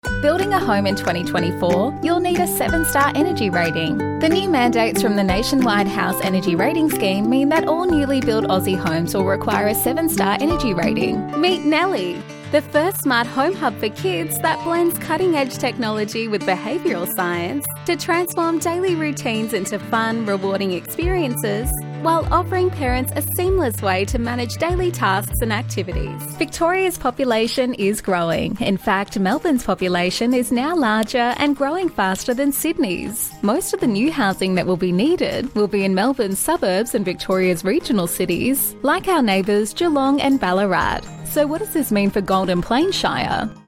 Australian female voiceover artist, with a voice often described as:
Recording from my sound engineer approved home recording studio
Explainer Videos
Friendly, Informed